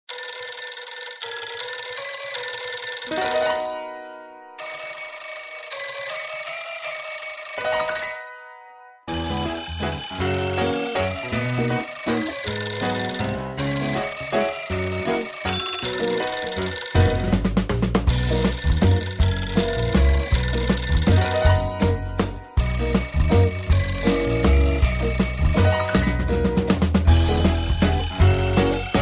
old-telephone-waltz_31168.mp3